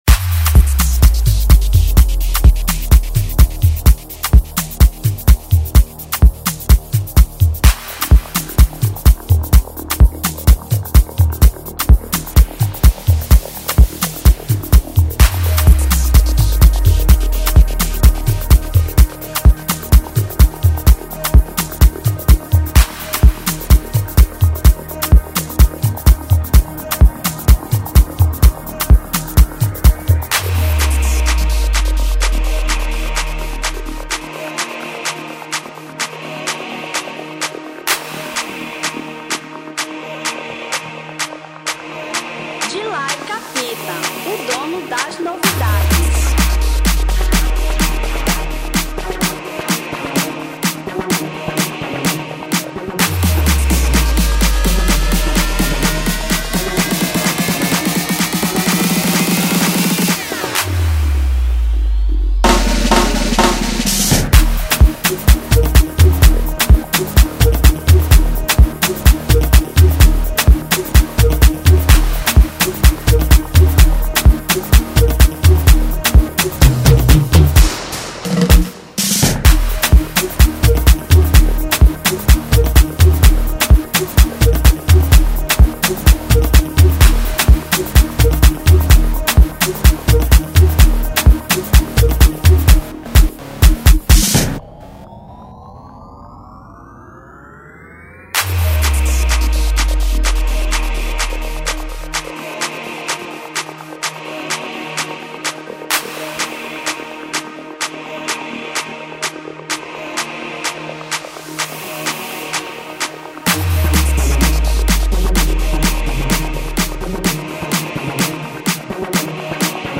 Instrumental 2020